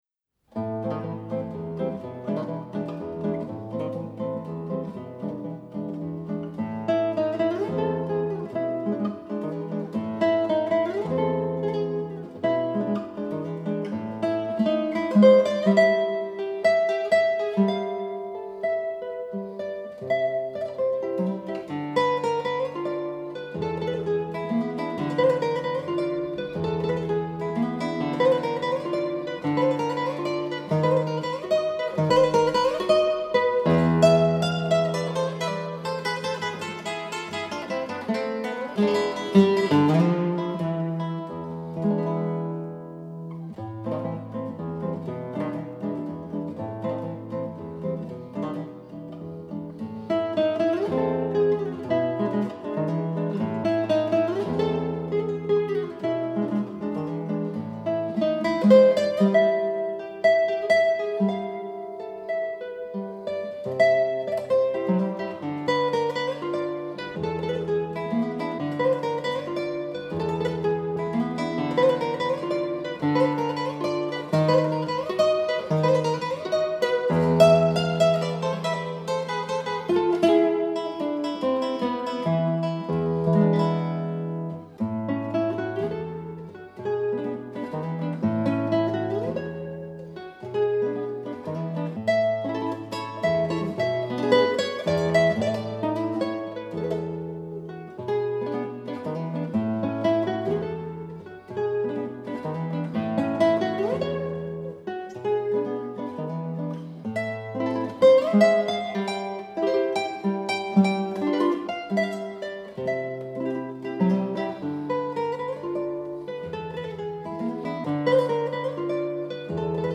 GUITARE
ORIENTATION CLASSIQUE